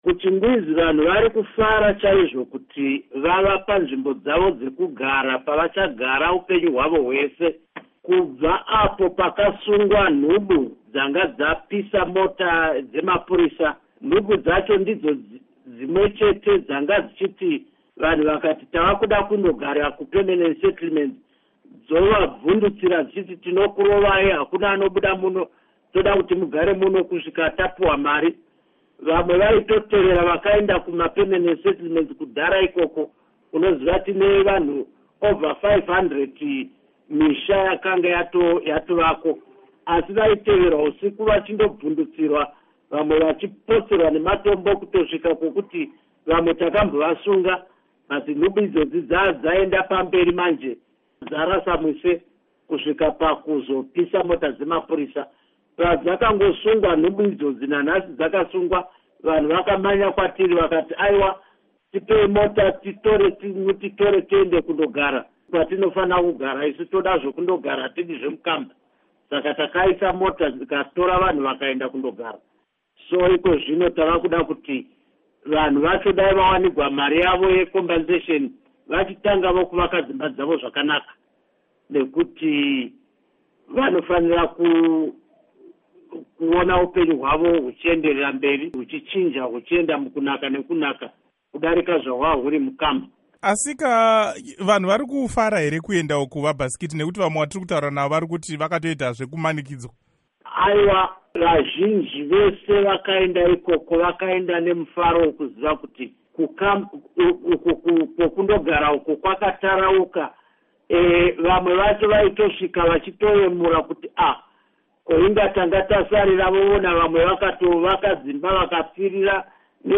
Embed share Hurukuro naVaKudakwashe Bhasikiti by VOA Embed share The code has been copied to your clipboard.